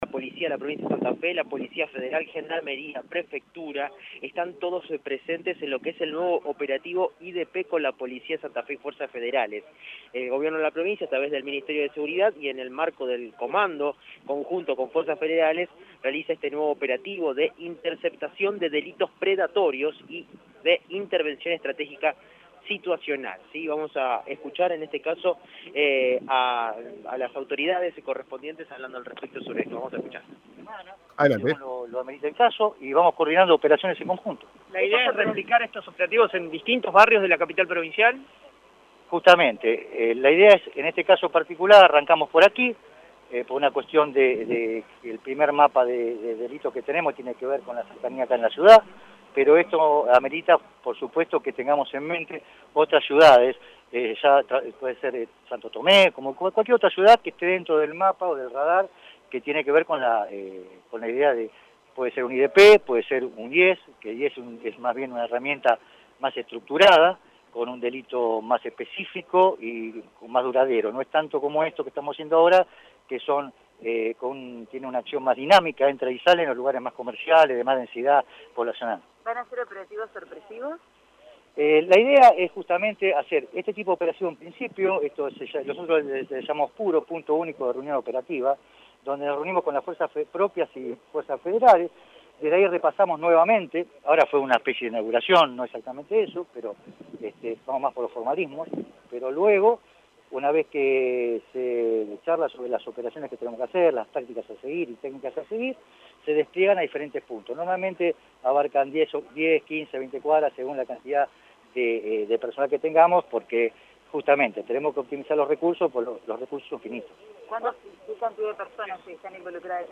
Presentaron el operativo de Interceptación de Delitos Predatorios (IDP) y de Intervención Estratégica Situacional (IES), en la Estación Belgrano de la ciudad de Santa Fe.
Informe